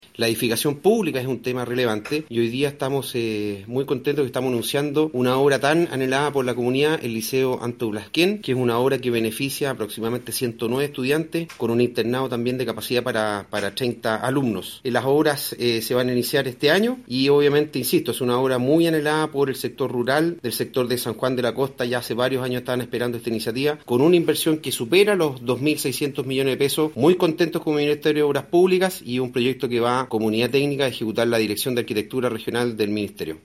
Al respecto, el seremi de Obras Públicas, James Fry señaló que están contentos de anunciar una obra anhelada por la comunidad que beneficiará a cerca de 109 estudiantes, donde las obras se iniciarán finalmente este año que ejecutará la Dirección de Arquitectura Regional del Ministerio.